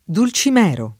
[ dul © im $ ro ]